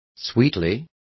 Also find out how dulcemente is pronounced correctly.